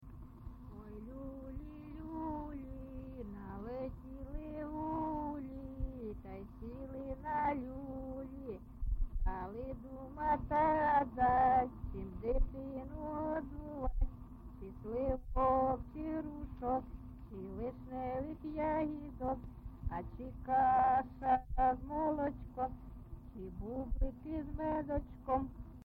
GenreLullaby
Recording locationMykhailivka, Shakhtarskyi (Horlivskyi) district, Donetsk obl., Ukraine, Sloboda Ukraine